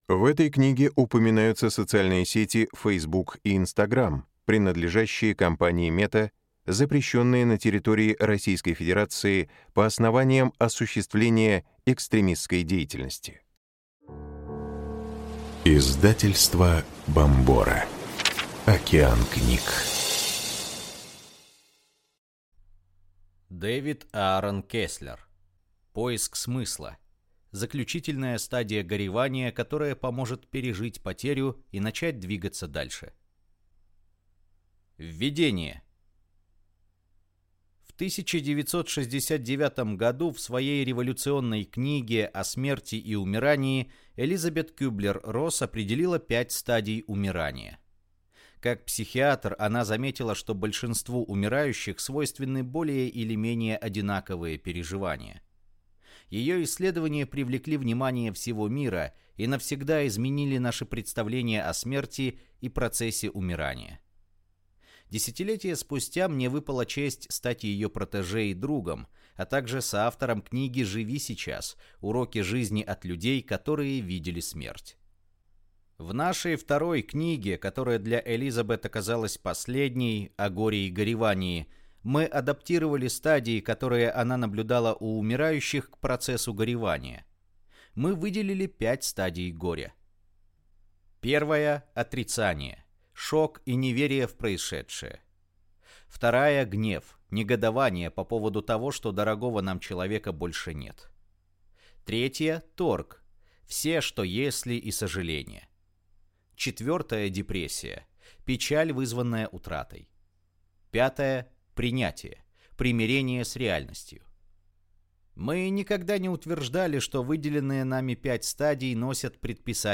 Аудиокнига Поиск смысла. Заключительная стадия горевания, которая поможет пережить потерю и начать двигаться дальше | Библиотека аудиокниг